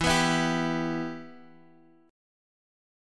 Em chord